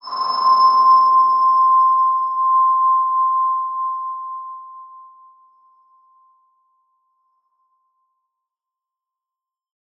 X_BasicBells-C4-ff.wav